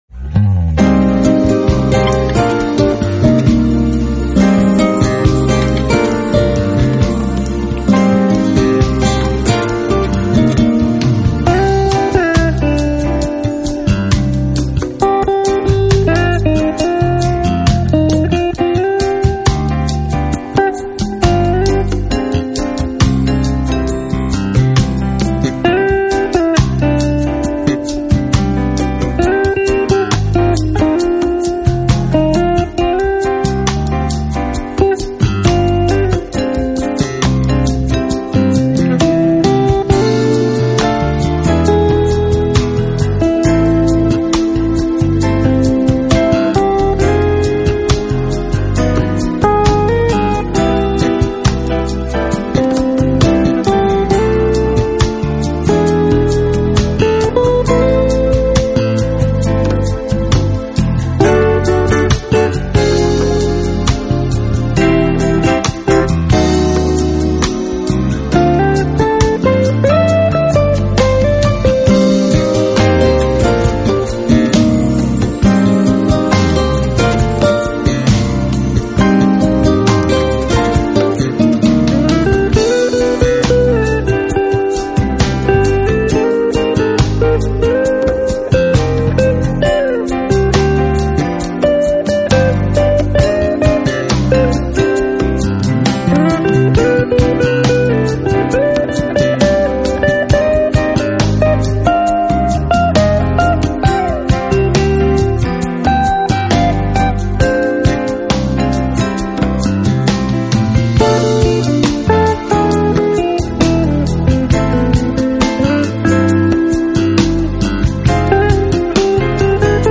sax
keyboards
bass
drums and percussion